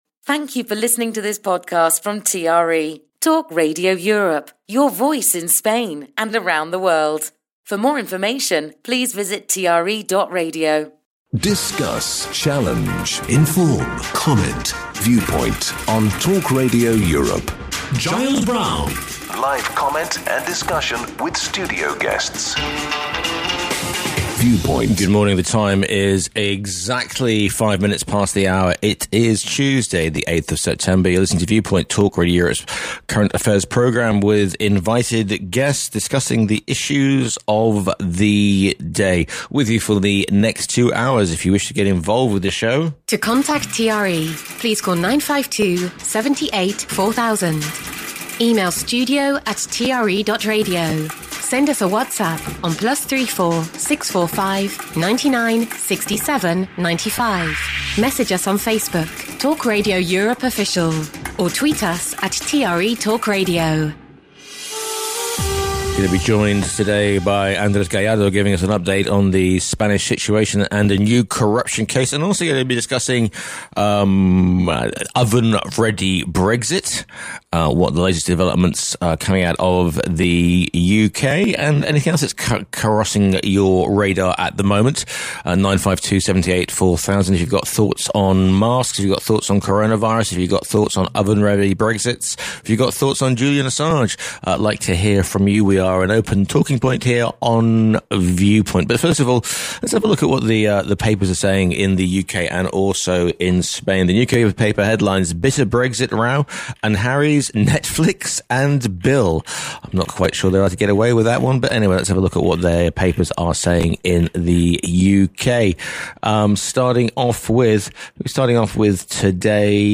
panel of guests